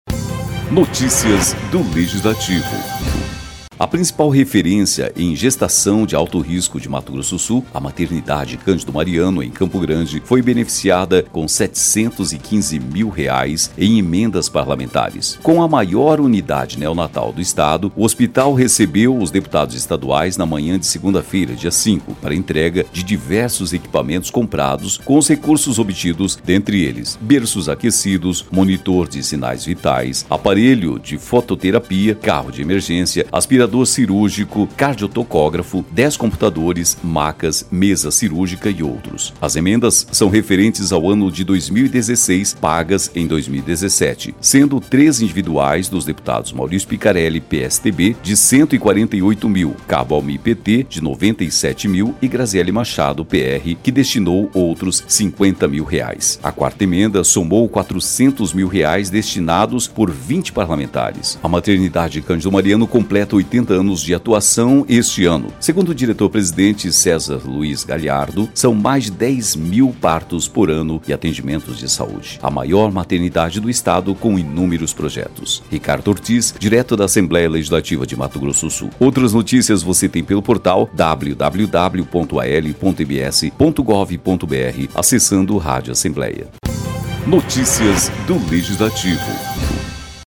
O presidente da Assembleia Legislativa, Junior Mochi, discursou em nome dos parlamentares.